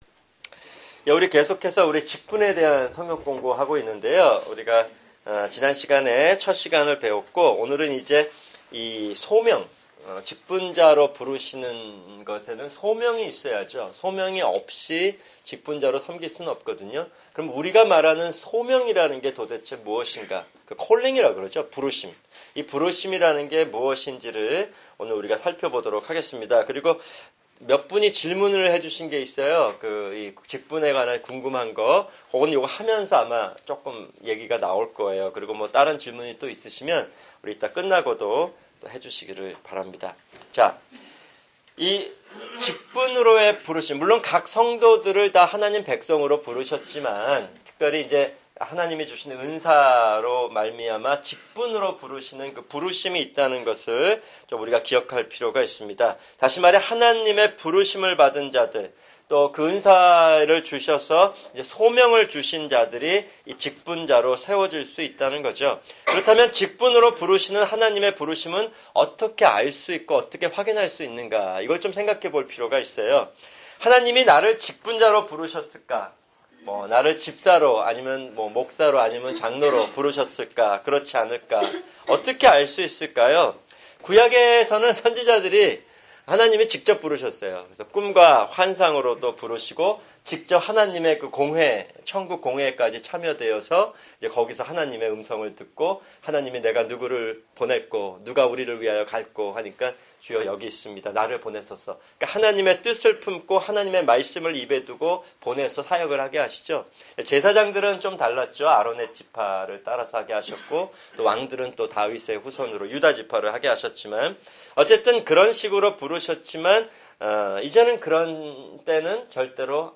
[주일 성경공부] 직분(2)